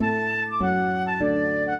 flute-harp
minuet0-2.wav